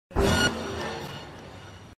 Cyberpunk Alert